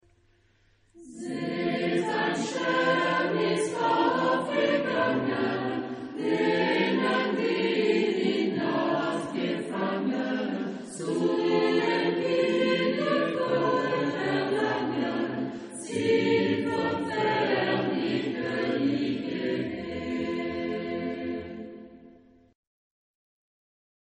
Genre-Style-Forme : Sacré ; Renaissance ; Motet
Type de choeur : SATB  (4 voix mixtes )
Tonalité : fa majeur